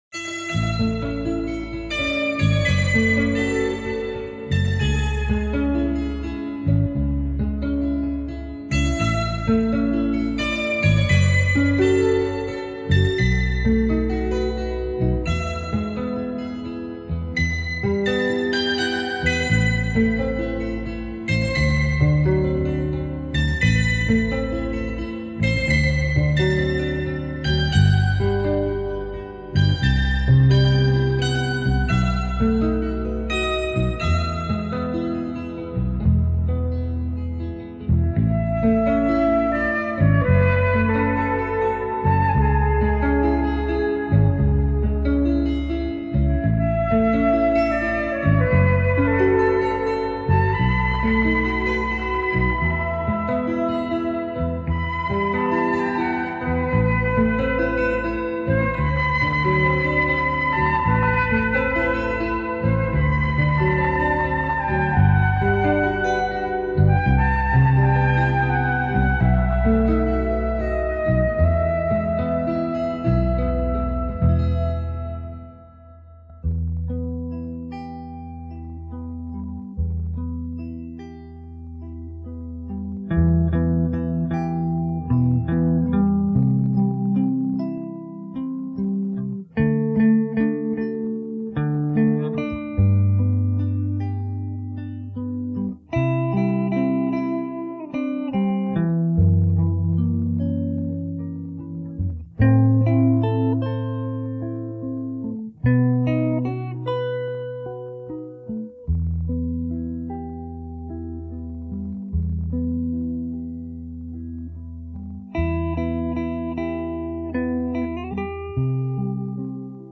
тоже красивая колыбельная